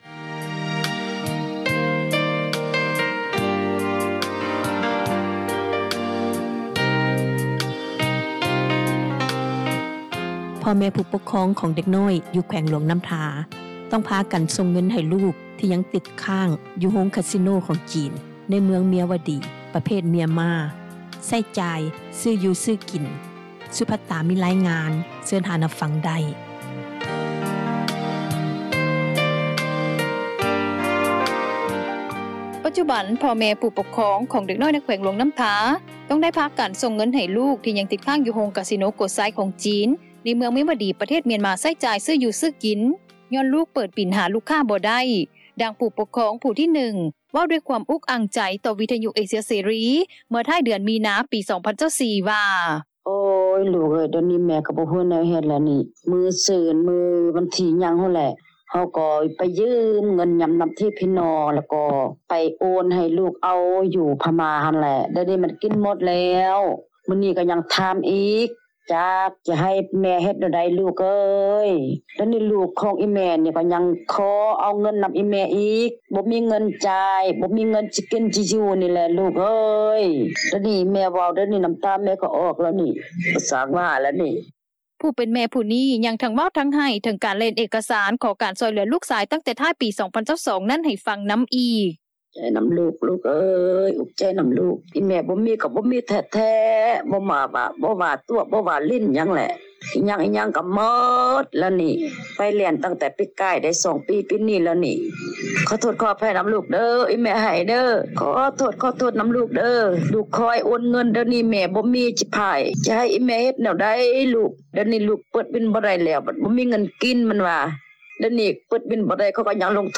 ຜູ້ເປັນແມ່ຜູ້ນີ້ ຍັງທັງເວົ້າທັງໄຫ້ ເຖິງການແລ່ນໜັງເອກະສານ ຂໍການຊ່ອຍເຫຼືອລູກຊາຍ ຕັ້ງແຕ່ທ້າຍປີ 2022 ນັ້ນ ໃຫ້ຟັງນໍາອີກ: